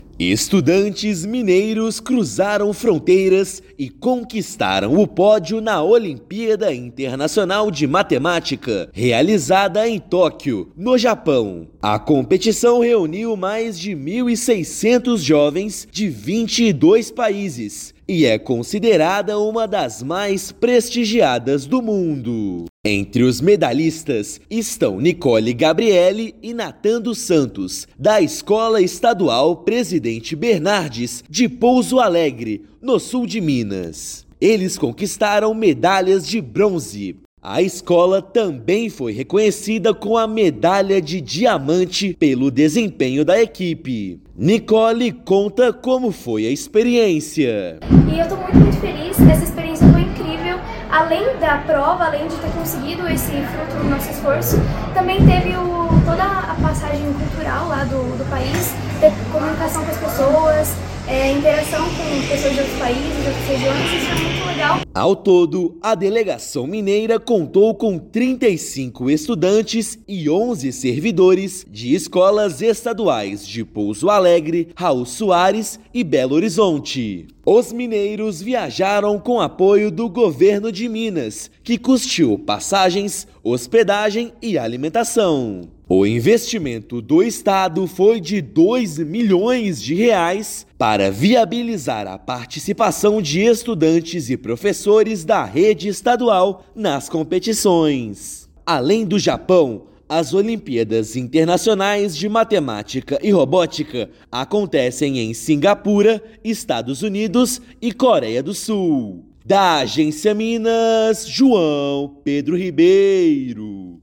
Delegação de escolas públicas de Minas Gerais brilha na AIMO 2025 e conquista quatro medalhas de bronze. Ouça matéria de rádio.